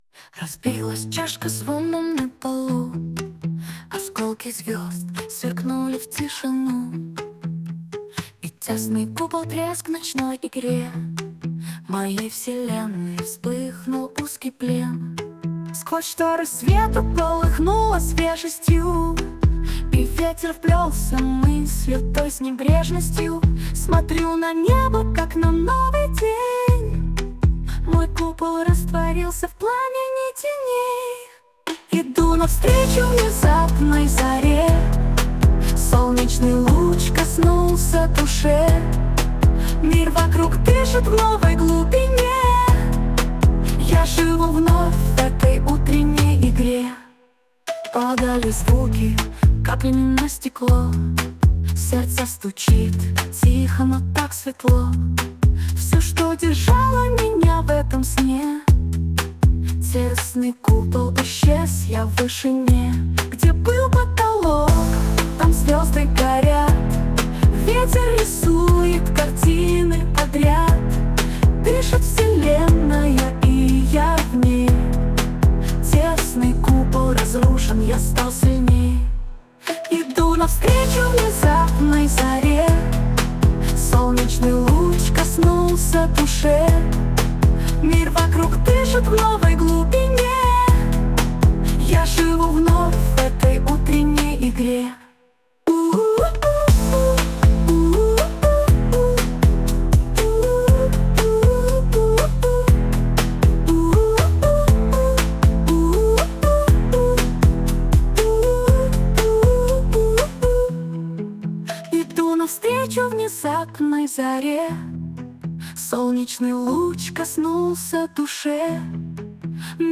Создано с помощью нейросети SUNO.